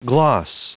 Prononciation du mot gloss en anglais (fichier audio)
Prononciation du mot : gloss